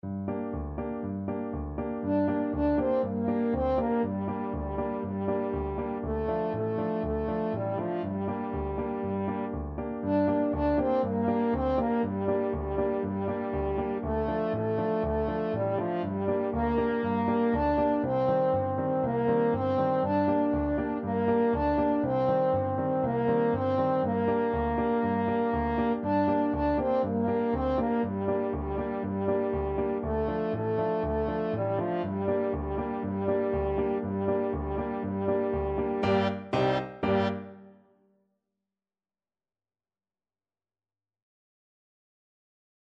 Allegro vivo (View more music marked Allegro)
4/4 (View more 4/4 Music)
World (View more World French Horn Music)